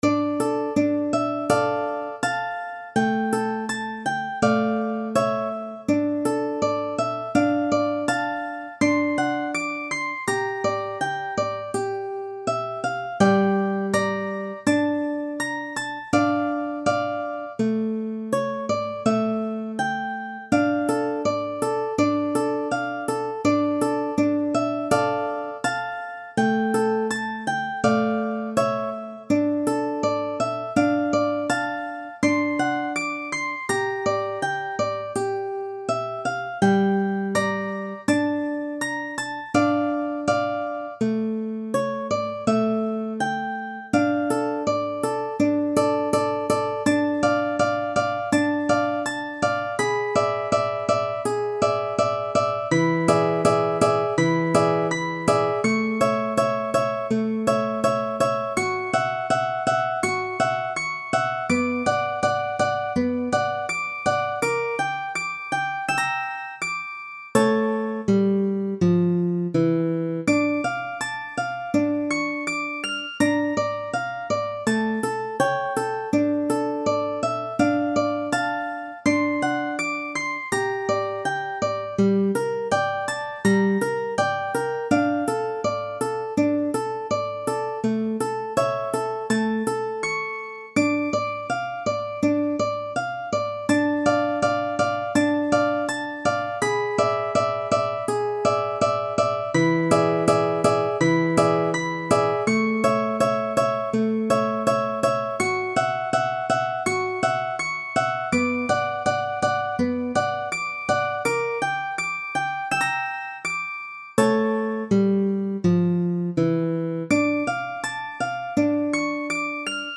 歌謡曲・演歌
楽譜の音源（デモ演奏）は下記URLよりご確認いただけます。
（この音源はコンピューターによる演奏ですが、実際に人が演奏することで、さらに表現豊かで魅力的なサウンドになります！）